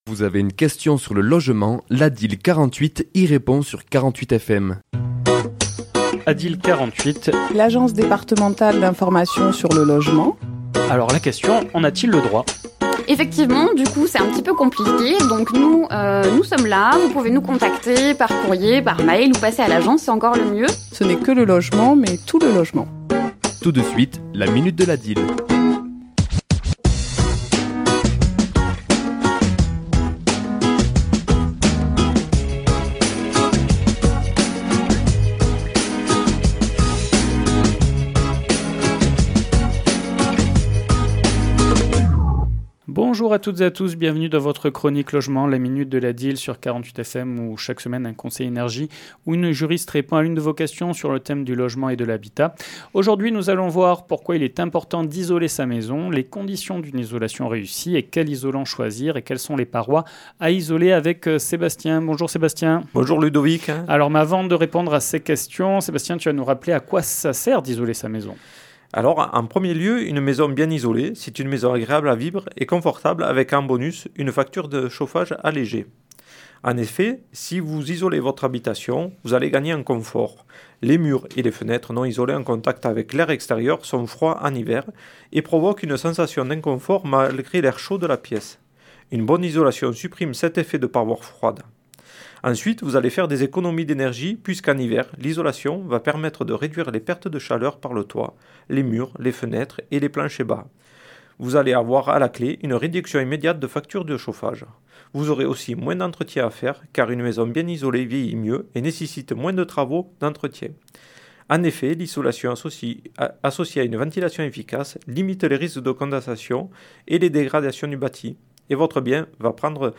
ChroniquesLa minute de l'ADIL
Chronique diffusée le mardi 7 janvier à 11h et 17h10